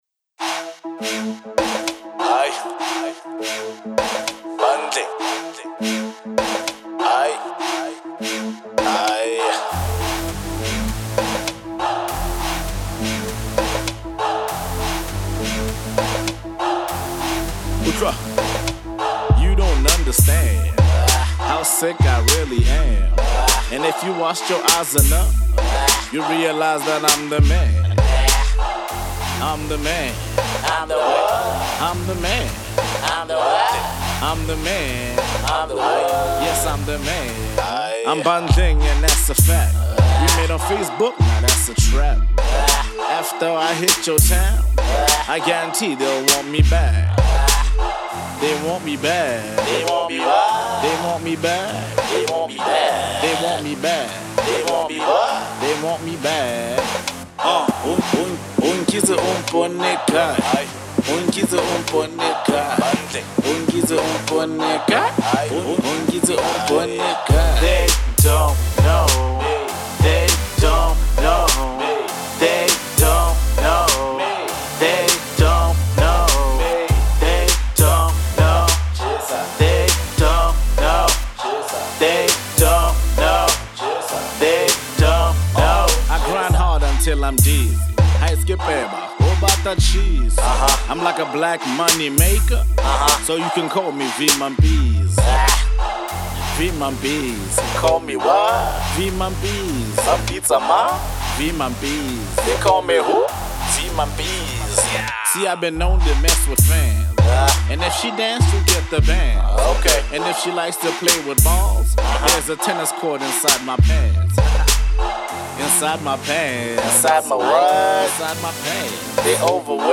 a Pure Rap song